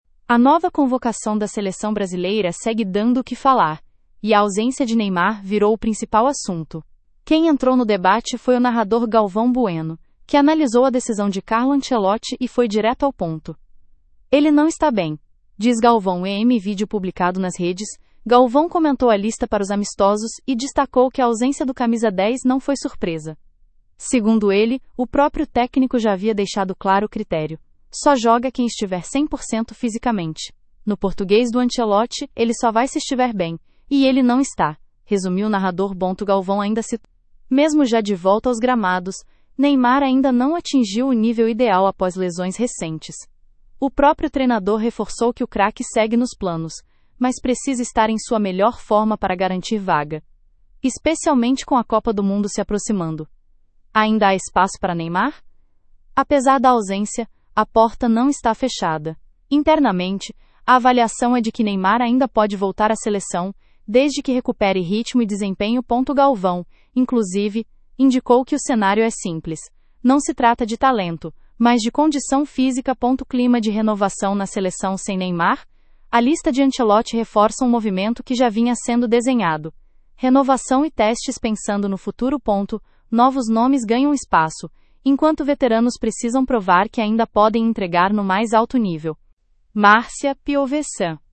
Em vídeo publicado nas redes, Galvão comentou a lista para os amistosos e destacou que a ausência do camisa 10 não foi surpresa.